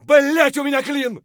gun_jam_3.ogg